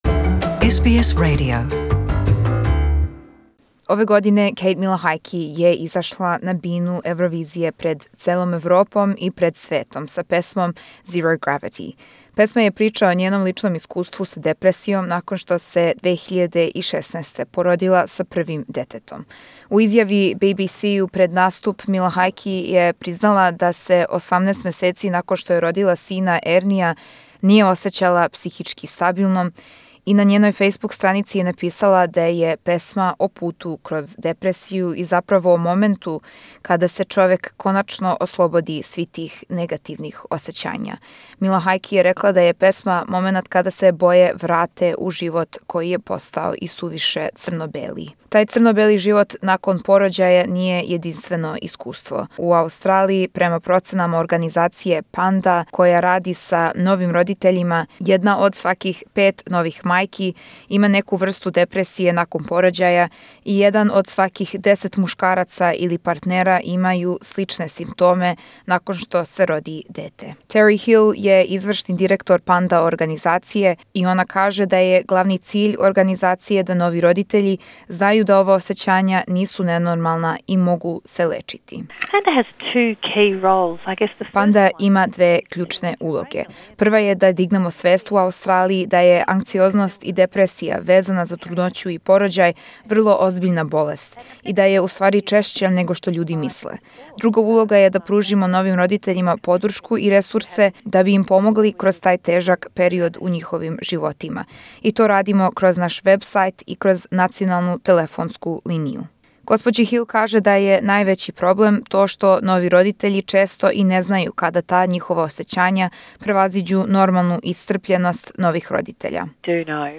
је разговарала са представницима организације ПАНДА за перинателну депресију